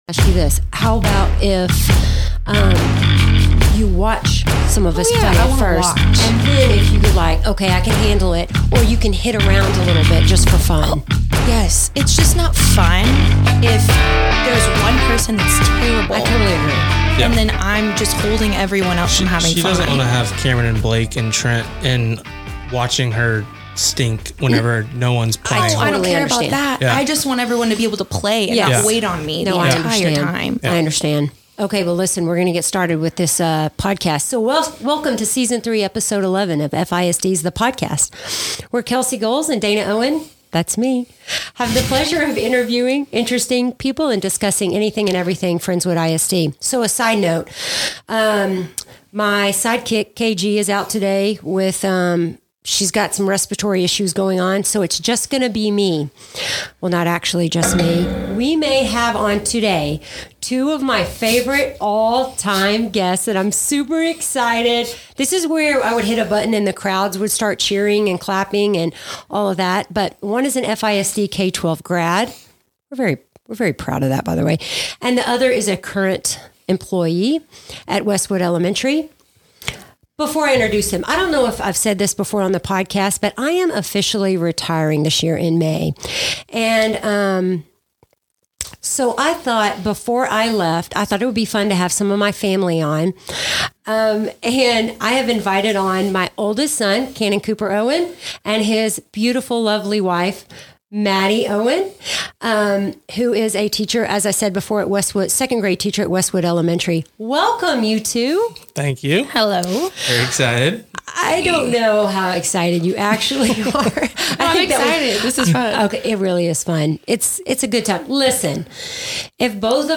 Laugh along as we explore parenting quirks, social media's impact on teachers, and even a fun interview question that sparks reflection. Tune in for a mix of heartfelt moments and light-hearted banter on FISD's The Podcast!